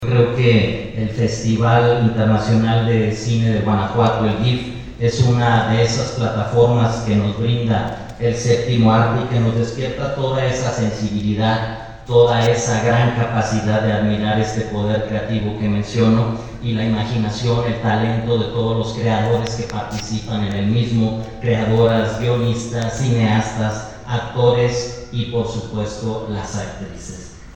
Rodolfo Gómez Cervantes, presidente municipal interino de Irapuato